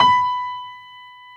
55p-pno29-B4.wav